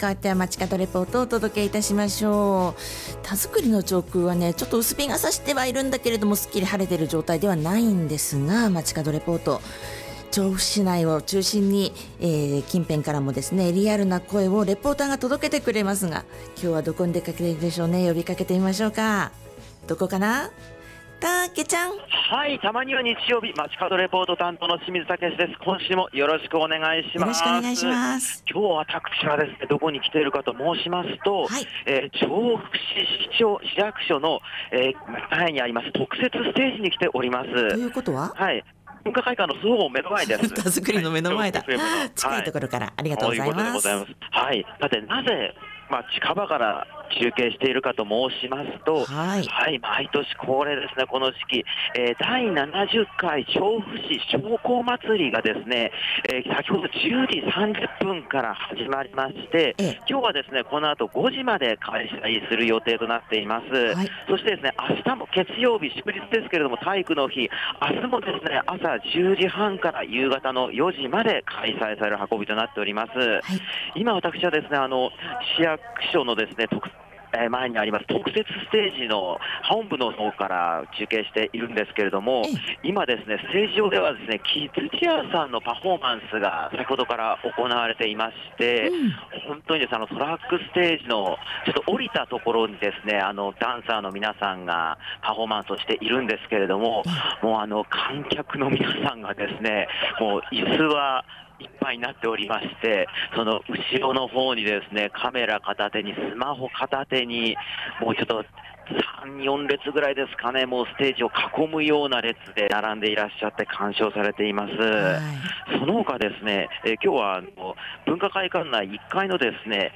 台風の影響で雲が多めの空の下からお届けした街角レポートは、 調布市市庁舎前で開催された「第70回 調布市商工まつり」についてレポートしてまいりました！